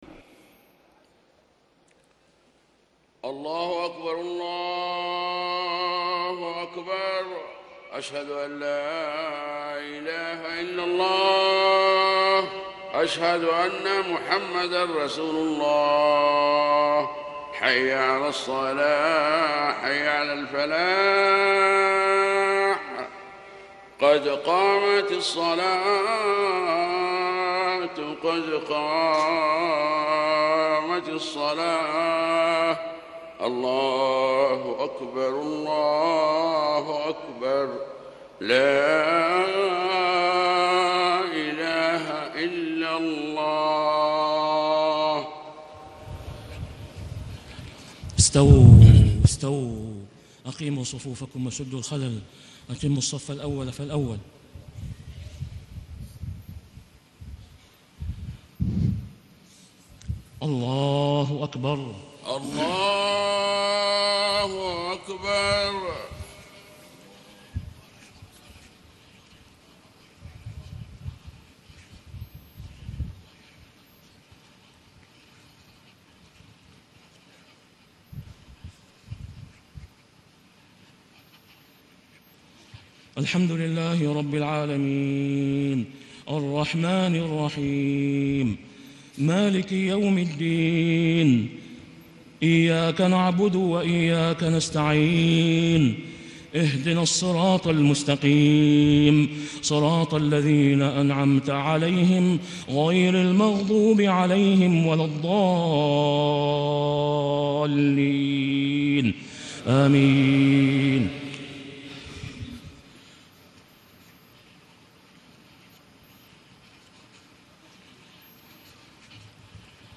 صلاة الجمعه 29 شعبان 1435 سورة الاعلى والغاشية > 1435 🕋 > الفروض - تلاوات الحرمين